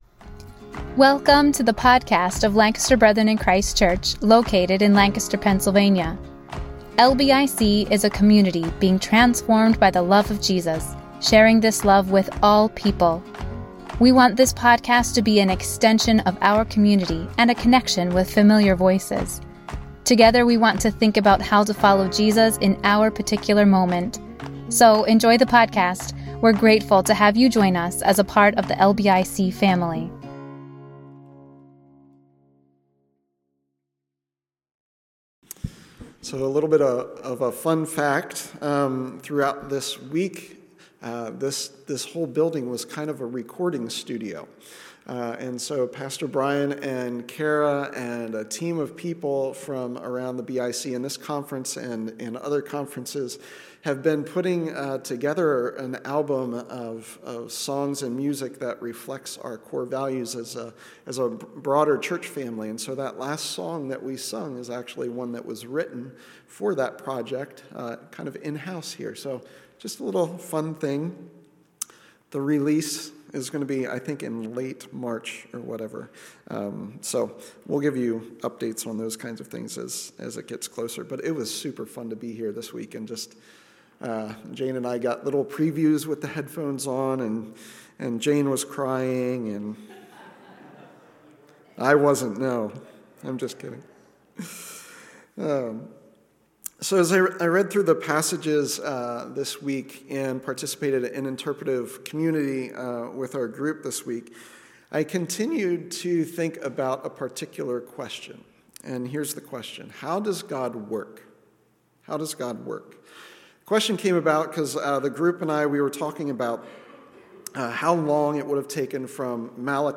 5th Sunday After Epiphany Service Message